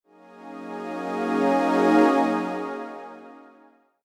Download Free Unique Logo Sound Effects